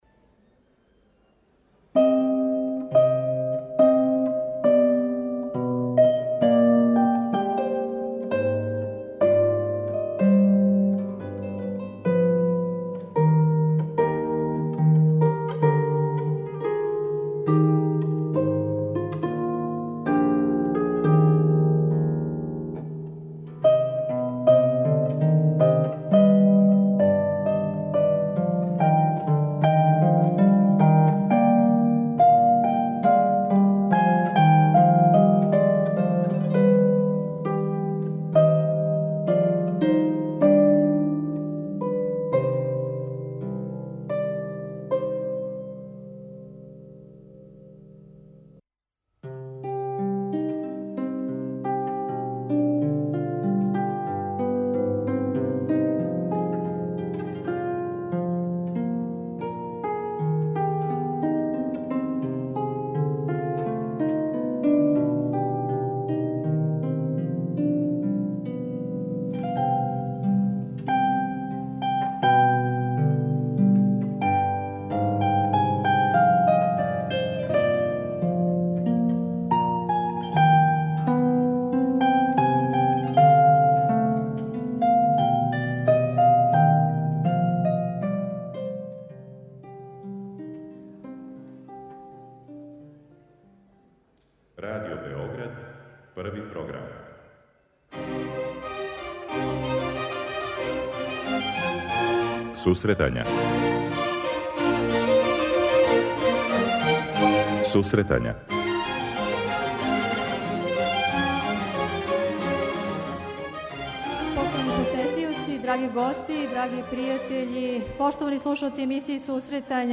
Променадни концерт: 30 година емисије Сусретања, пренос